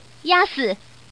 Index of /fujian_pw_test/update/3227/res/sfx/common_woman/